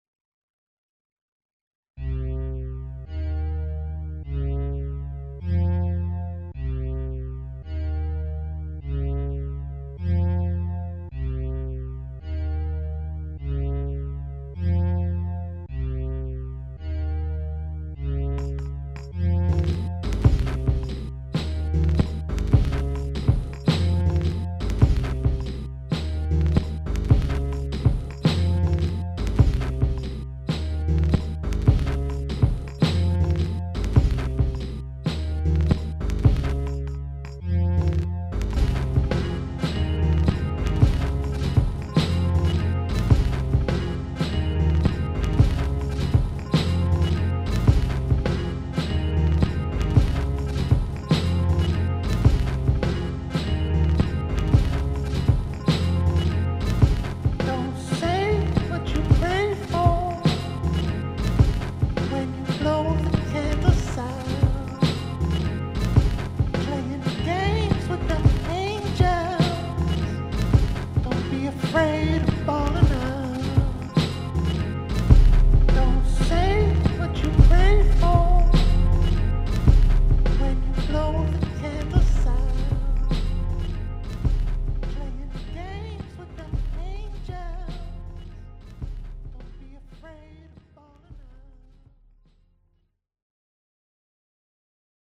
Some records, proteus, tambourine played with drum sticks and some falsettooooo sampled into OT